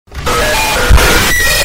ambush jumpscare from roblox doors for us sound effects
ambush-jumpscare-from-roblox-doors-for-us